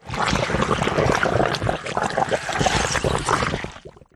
Blood_Sucking1.wav